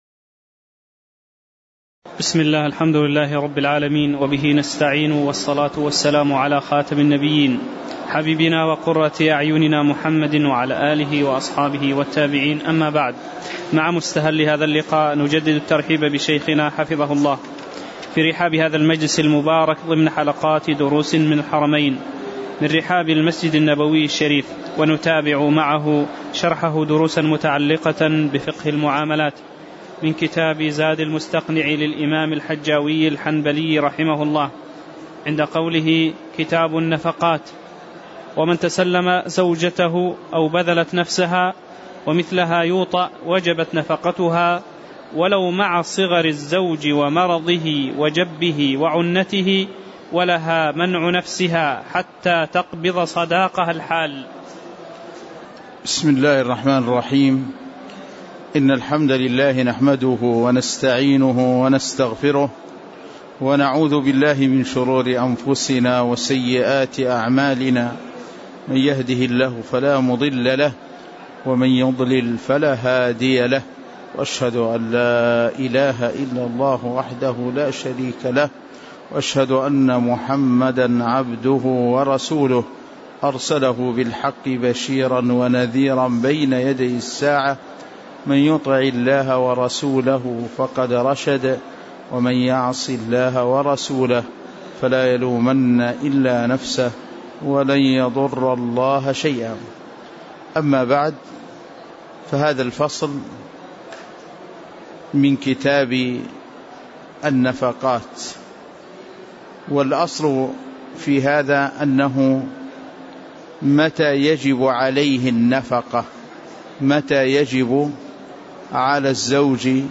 تاريخ النشر ٢١ صفر ١٤٣٨ هـ المكان: المسجد النبوي الشيخ